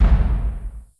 OnSkipButton.wav